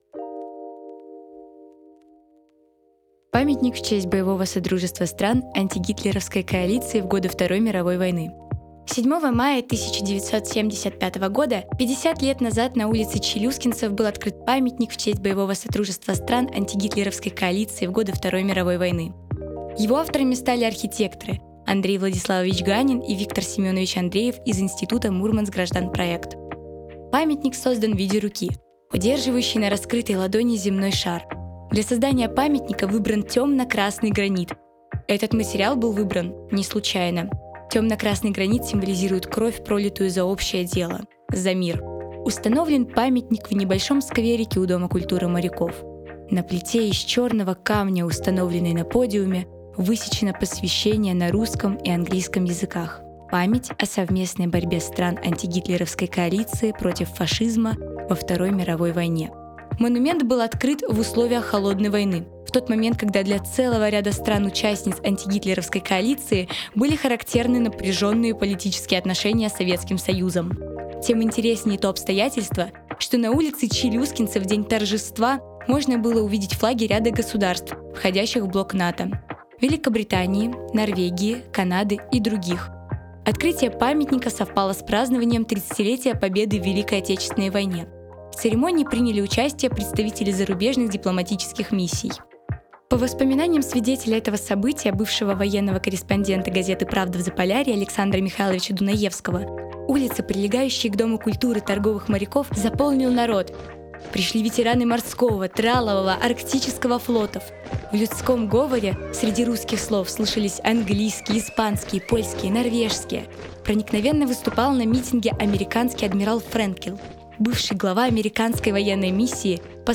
Аудиоэкскурсия «Памятник антигитлеровской коалиции»
Предлагаем послушать новую аудиоэкскурсию, записанную в рамках волонтерского туристического проекта «51 история города М»